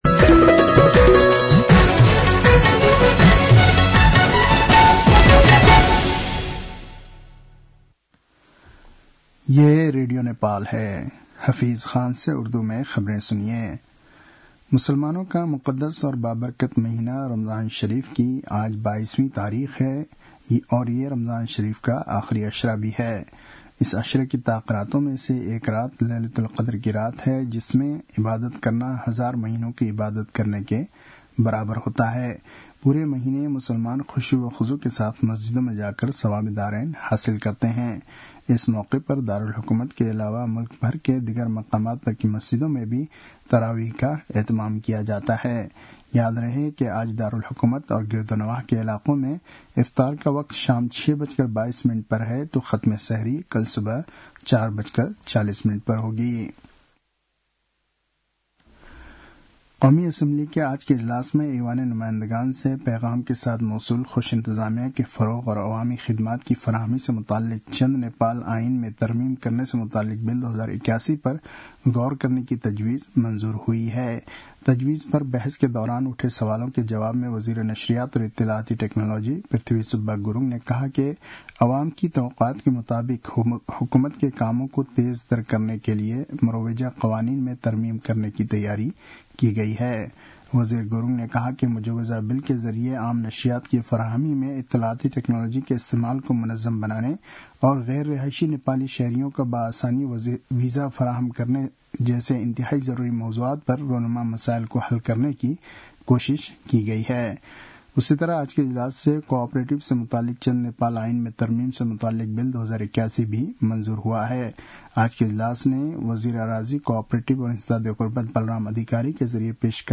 उर्दु भाषामा समाचार : १० चैत , २०८१